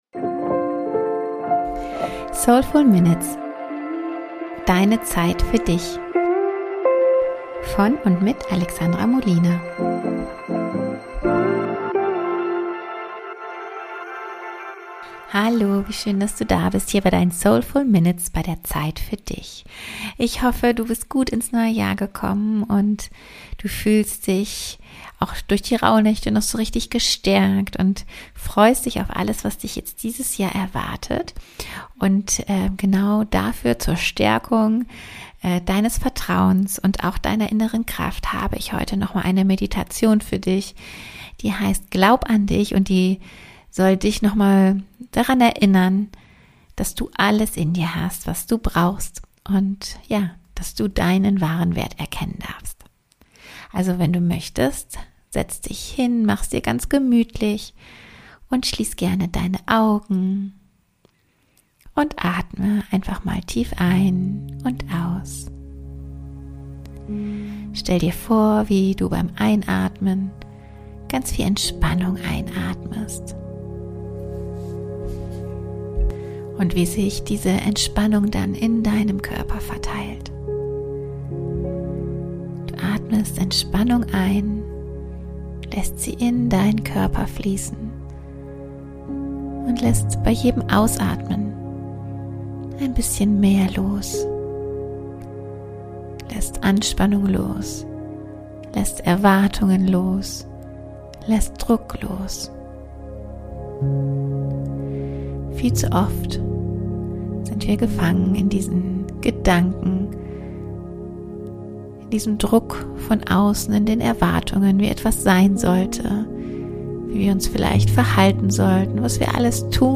Glaub an dich! Meditation ~ Soulful Minutes - Zeit für dich Podcast